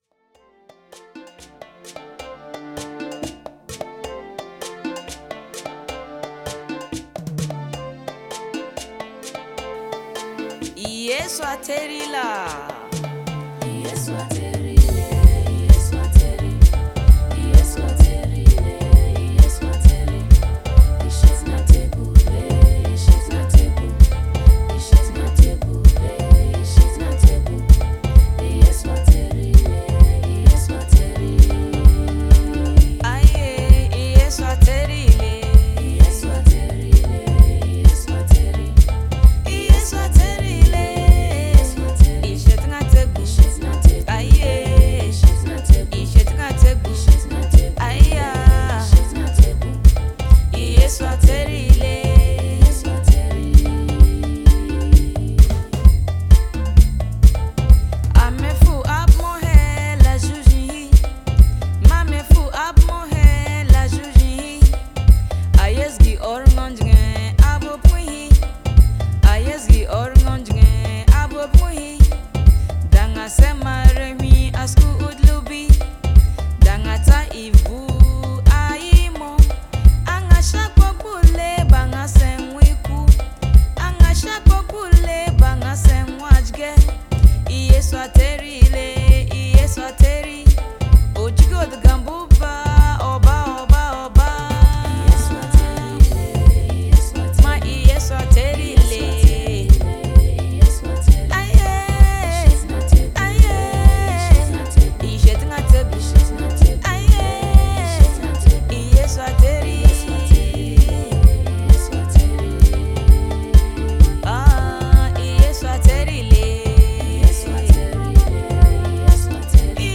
spirited and uplifting songs